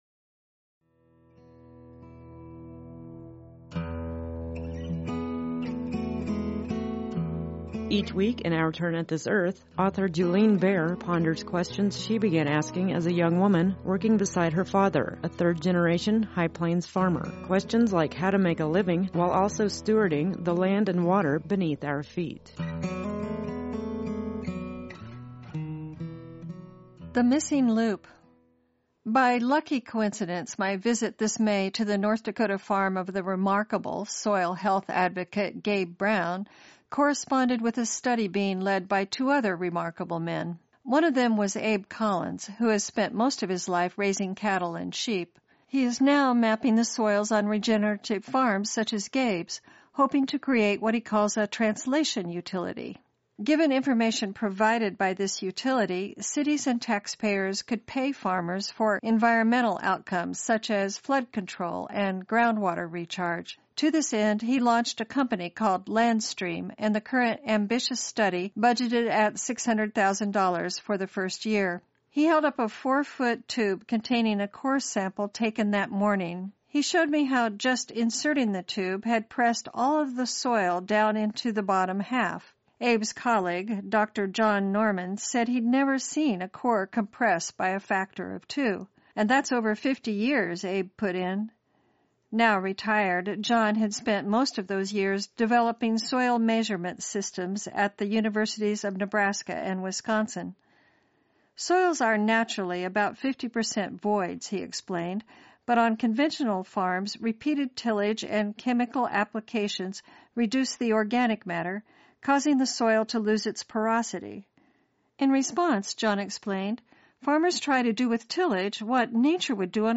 “Our Turn at this Earth” airs weekly on HIgh Plains Public Radio.